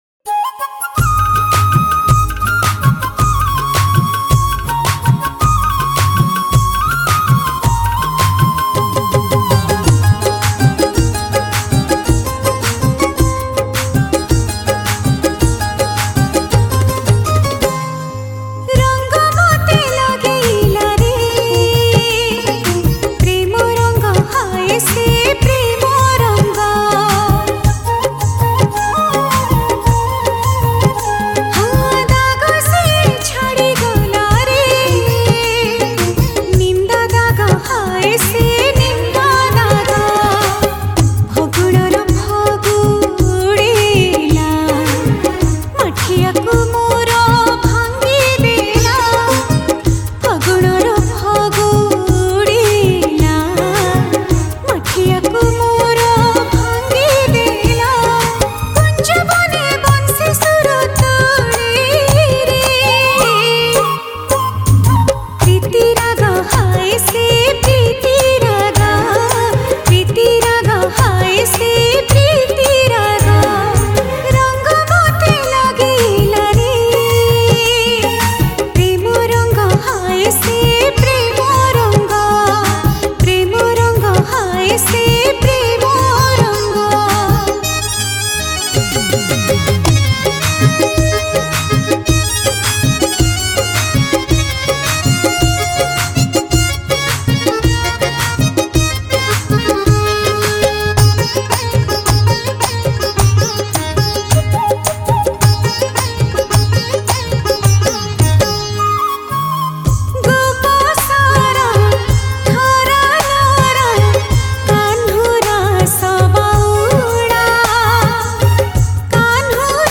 Odia New Bhajan 2025